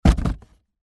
Звуки картонной коробки
Коробку с тяжелой начинкой швырнули на пол